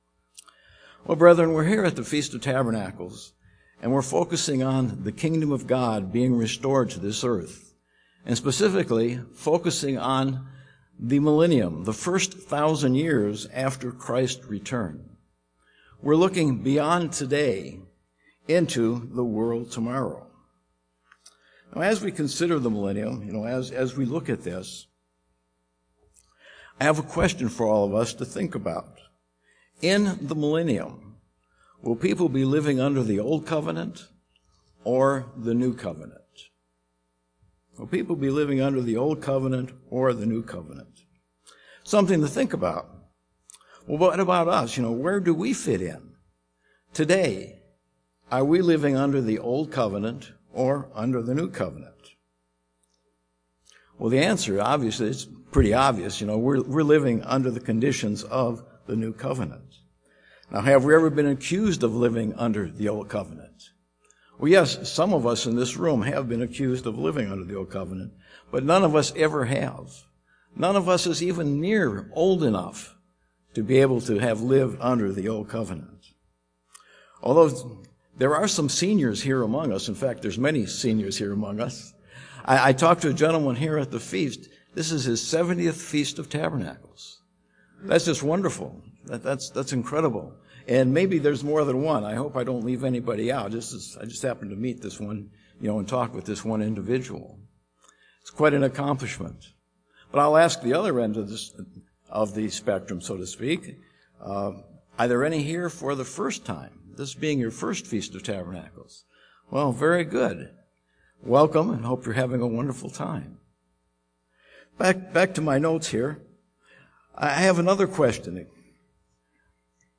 This sermon was given at the Pewaukee, Wisconsin 2020 Feast site.